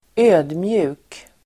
Uttal: [²'ö:dmju:k]